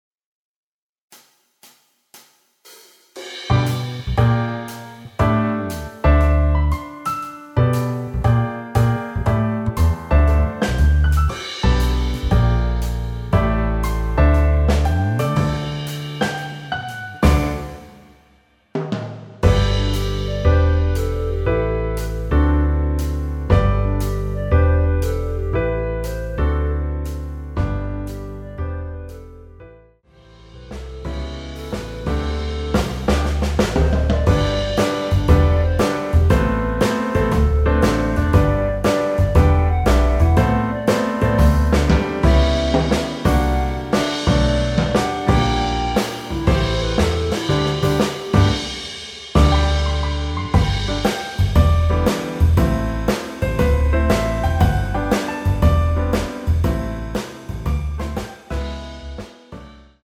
원키 멜로디 포함된 MR 입니다.(미리듣기 참조)
앞부분30초, 뒷부분30초씩 편집해서 올려 드리고 있습니다.
중간에 음이 끈어지고 다시 나오는 이유는